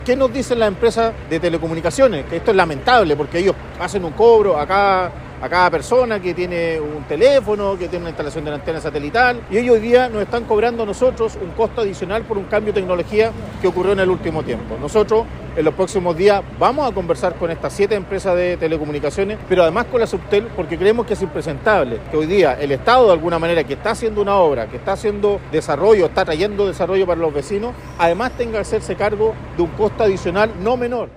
Los nuevos postes ya están instalados, pero los antiguos no se pueden retirar hasta realizar el traslado de los cables y ahí está el problema, comentó el alcalde de Talcahuano, Eduardo Saavedra.
cuna-postes-alcalde.mp3